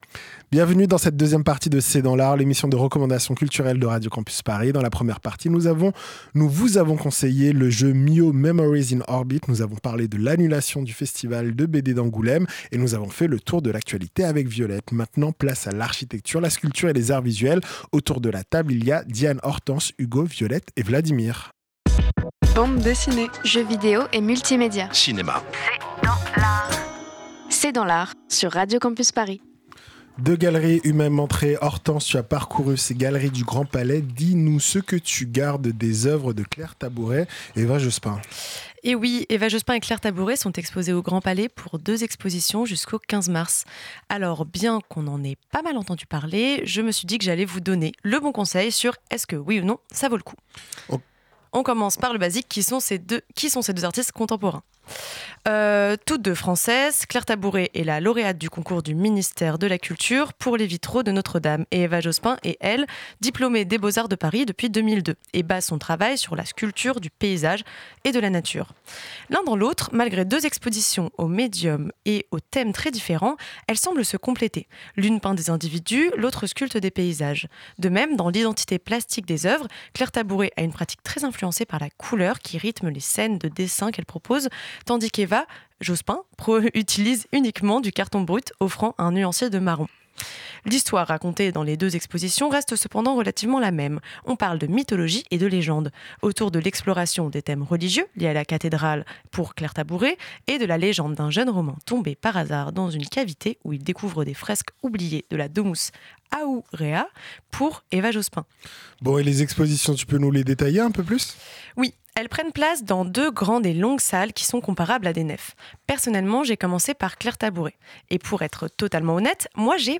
C’est dans l’art, l’émission de recommandation culturelle de Radio Campus Paris.